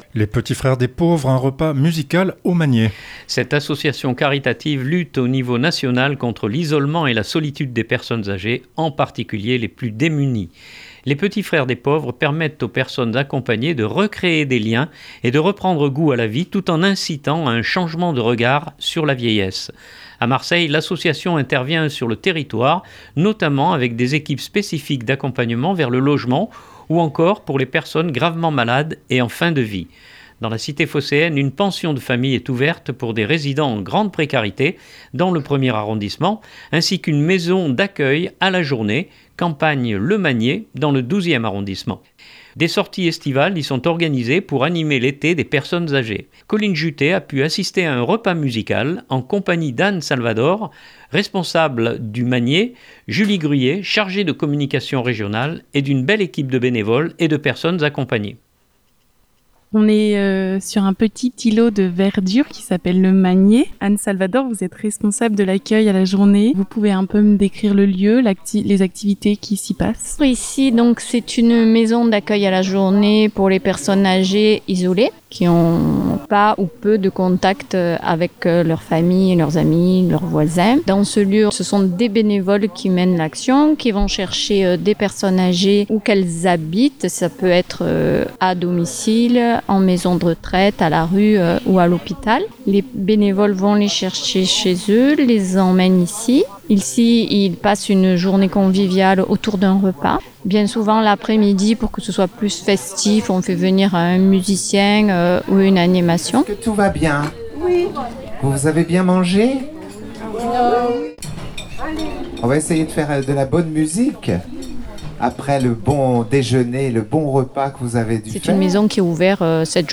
Les petits frères des pauvres : un repas musical au Manier